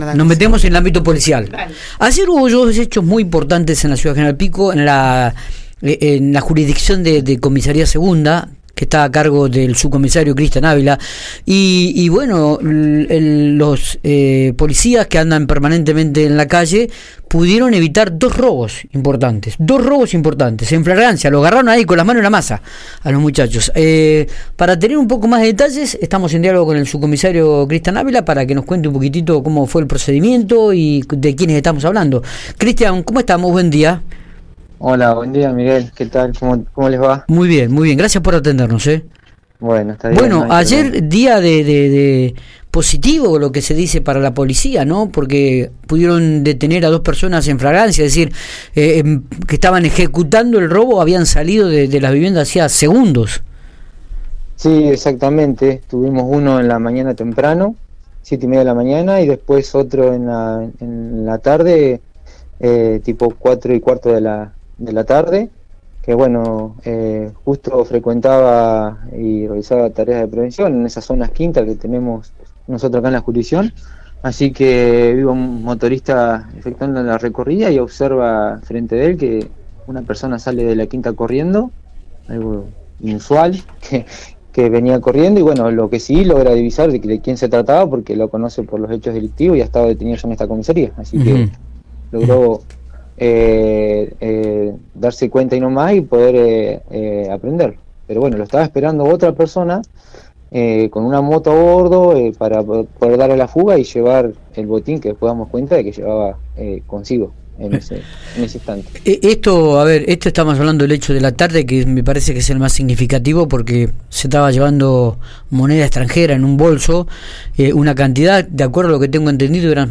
Audio| Entrevista